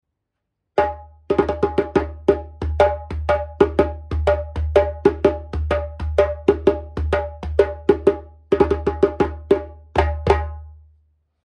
ゲニ材特有の硬質なハイを優しくするためにマリのやや厚めの皮を張り込んでます。3音バランスいいです。低音もズシンときます。